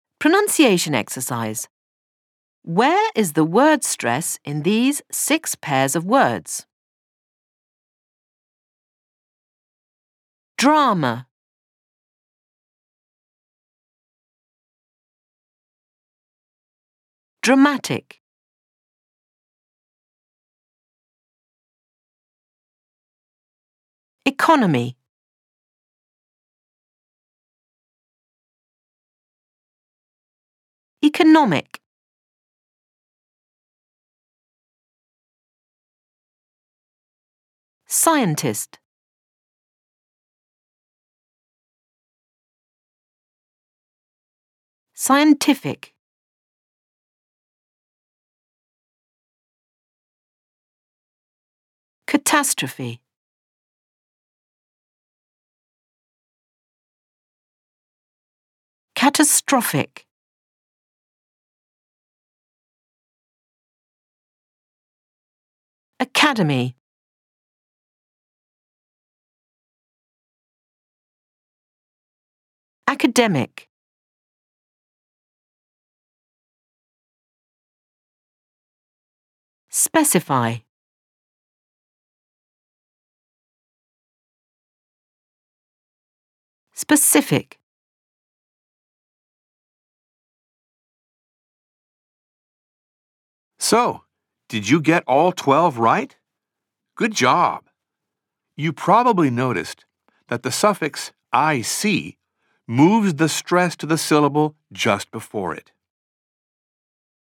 TDYN0356_05_FULL_PRONUNCIATION_T005_words ending with ic